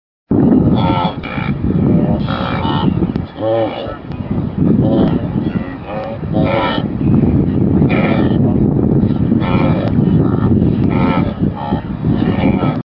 Sound of the Wildebeest The Wildebeest have kind of a snort to them, where as, the Zebra have a kind of jungle sound.
Wildebeest.mp3